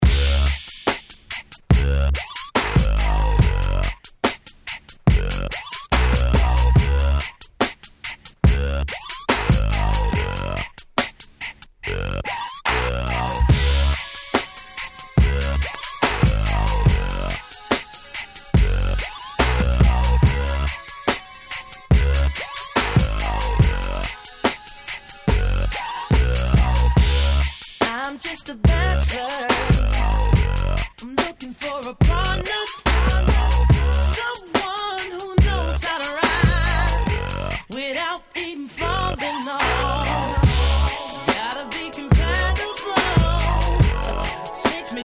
96' Super Hit R&B !!
鉄板中の鉄板Slow !!